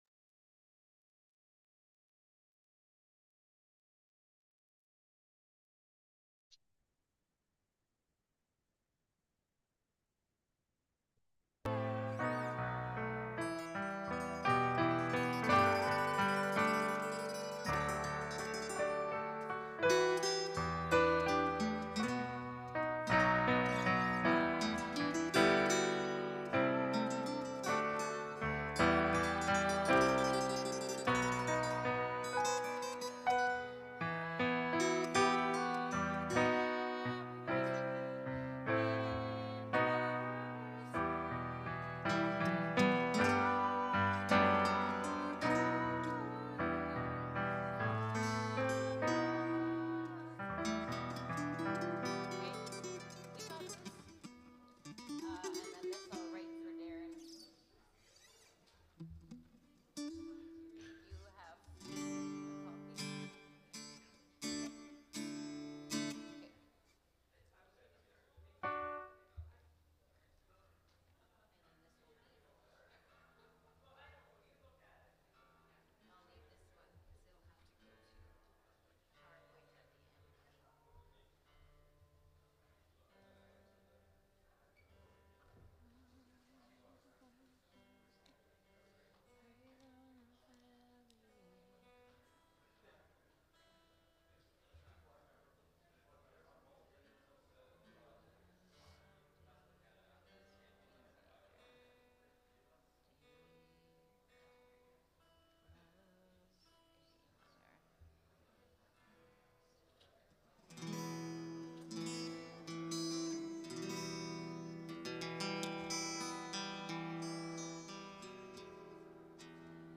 Note: Speaking begins around the 42 minute mark.